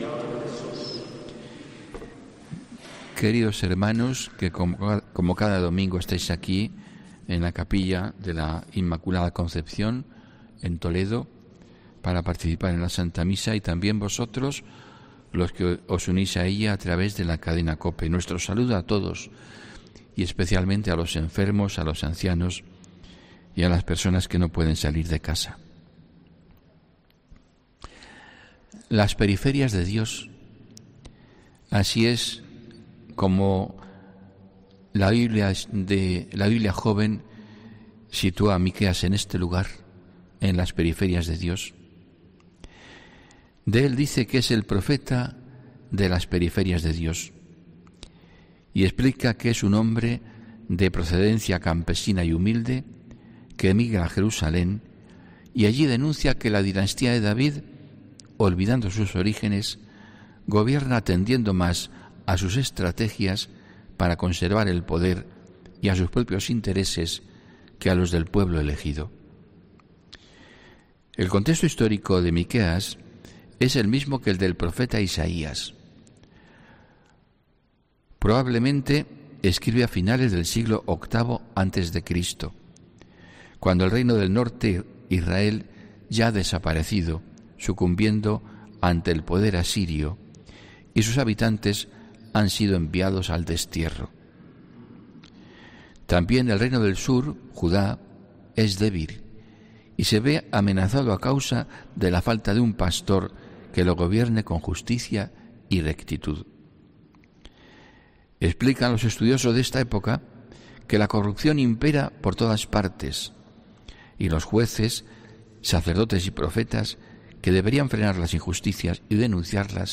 HOMILÍA 19 DICIEMBRE 2021